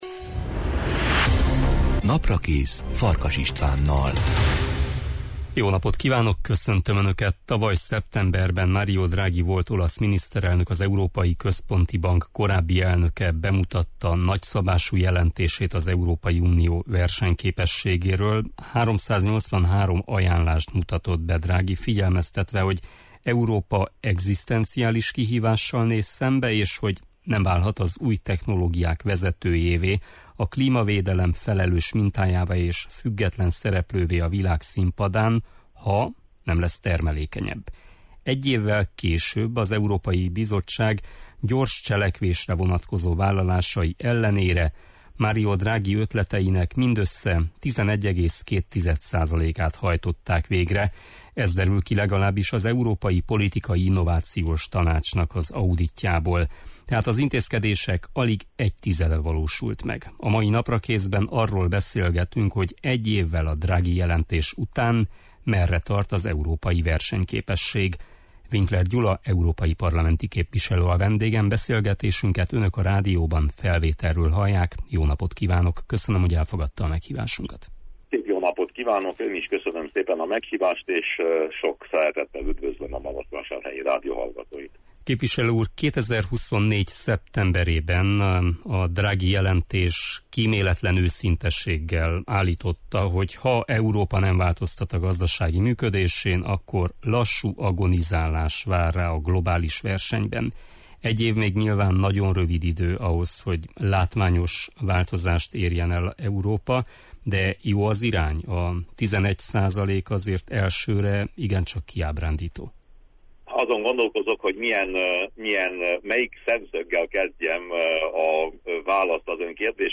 Winkler Gyula EP-képviselő a Naprakész vendége.